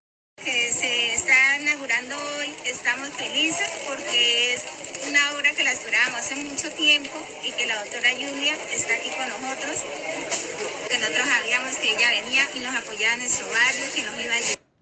AUDIO HABITANTE.mp3